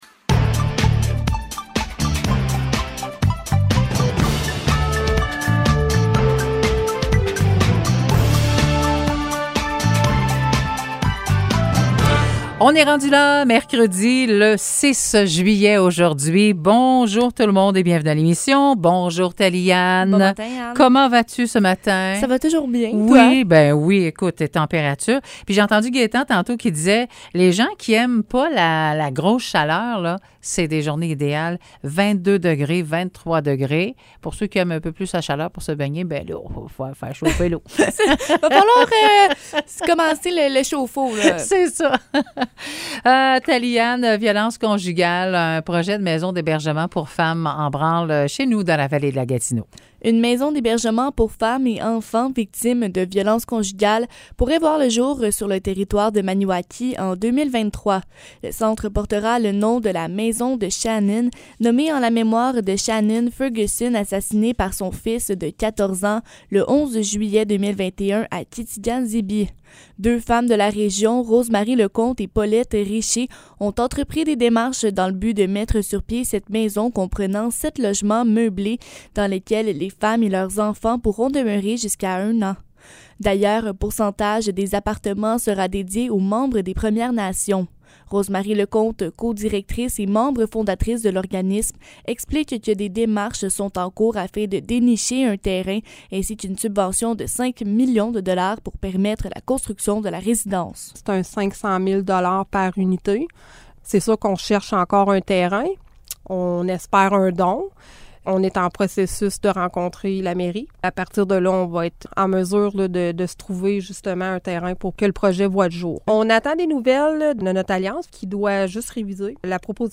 Nouvelles locales - 6 juillet 2022 - 9 h